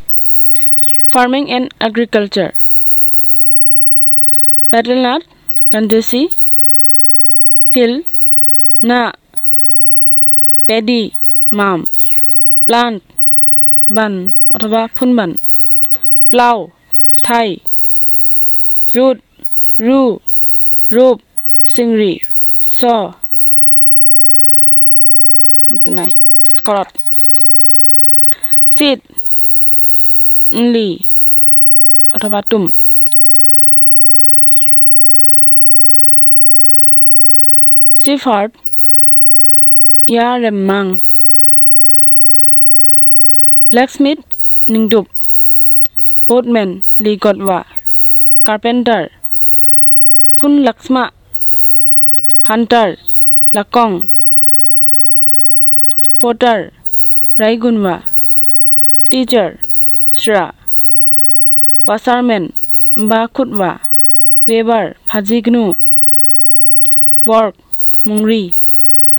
Elicitation of words about farming and agriculture and other occupations